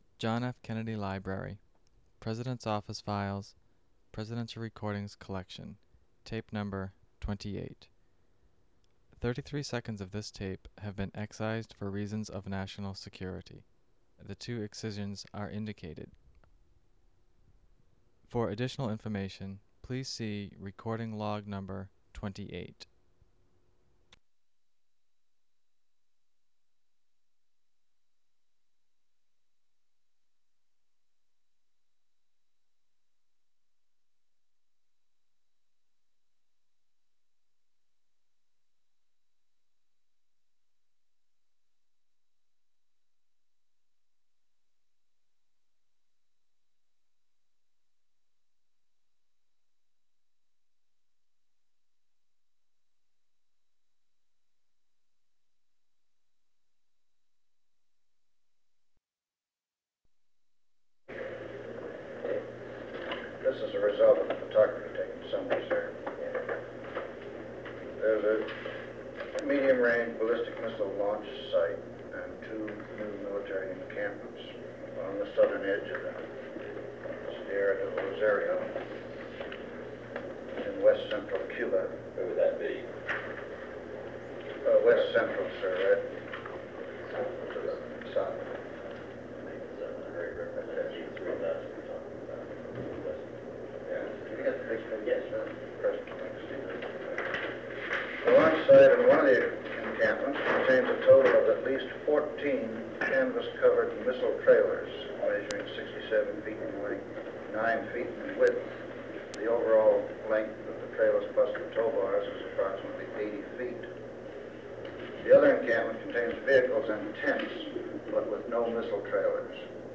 Meeting on the Cuban Missile Crisis
Secret White House Tapes | John F. Kennedy Presidency Meeting on the Cuban Missile Crisis Rewind 10 seconds Play/Pause Fast-forward 10 seconds 0:00 Download audio Previous Meetings: Tape 121/A57.